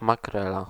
Ääntäminen
US : IPA : [ˈmæk.ə.ɹəl]